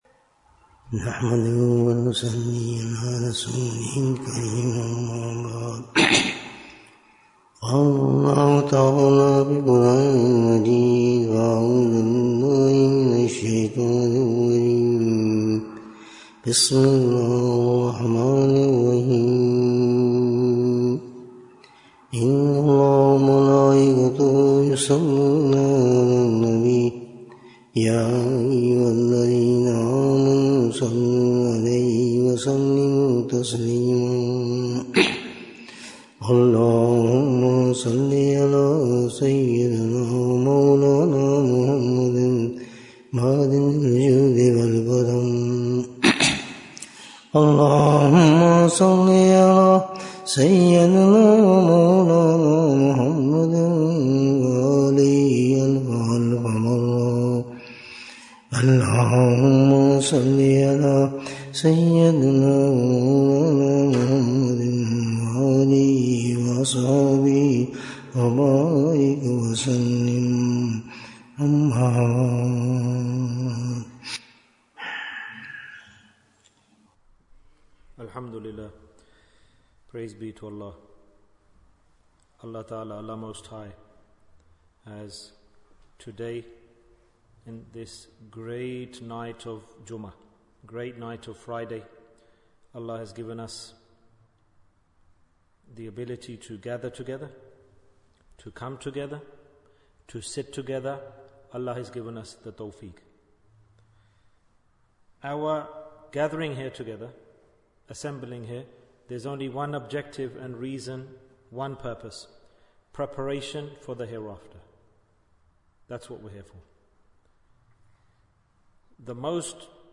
Bayan, 55 minutes15th August, 2024